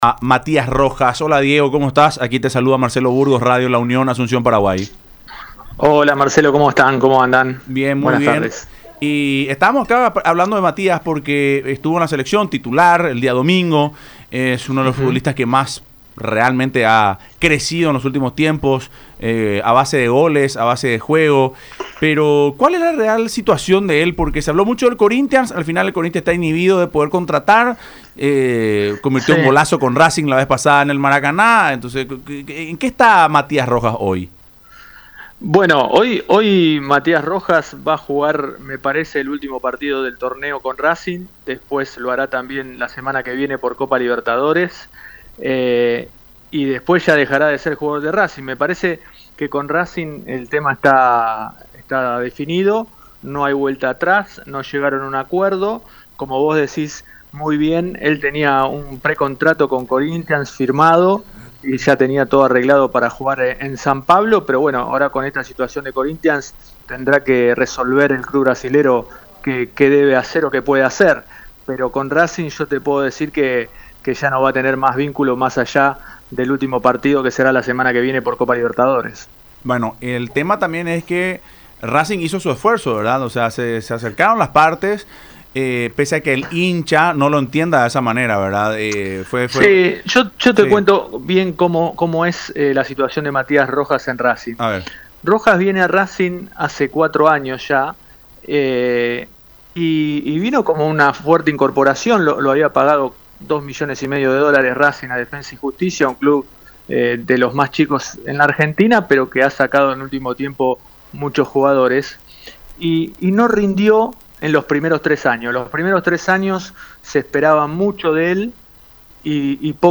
En contacto con Fútbol Club, a través de radio la Unión y Unión TV, comentó que la relación entre Rojas y Racing se deterioró en diciembre. La Academia rechazó una oferta Qatar, algo que disgustó enormemente al entorno del jugador.